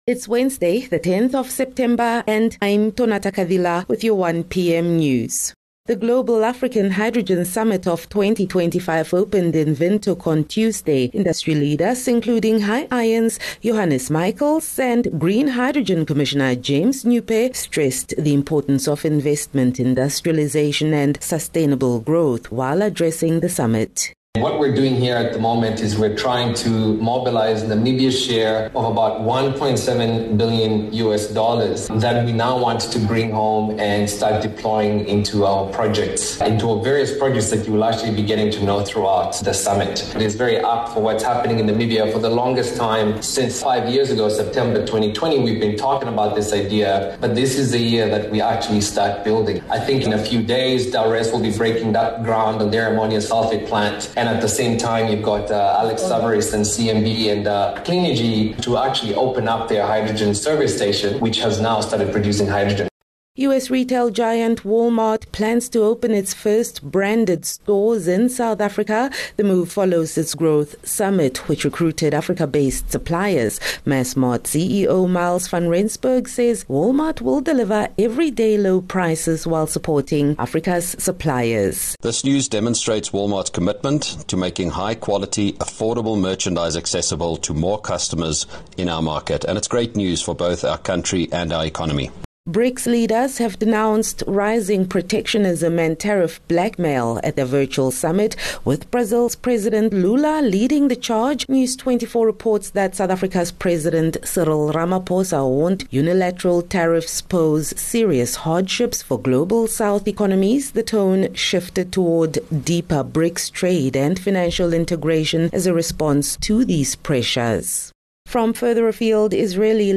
10 Sep 10 September - 1 pm news